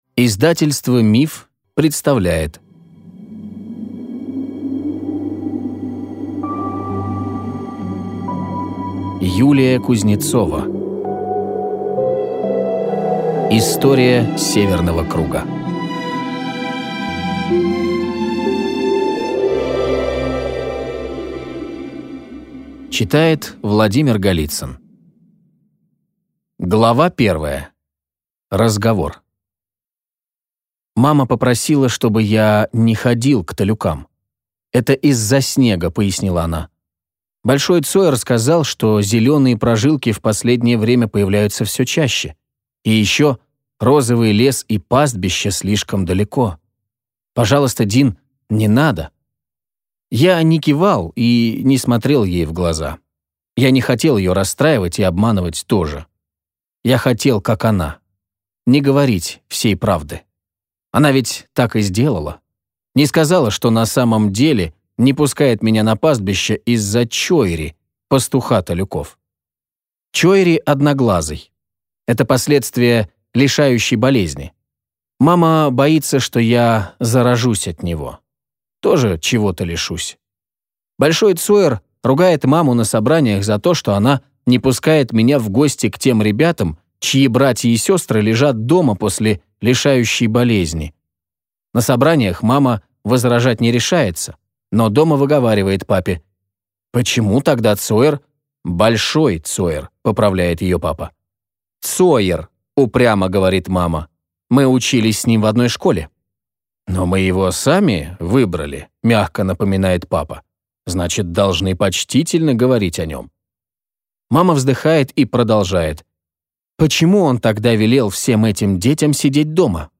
Аудиокнига История Северного круга | Библиотека аудиокниг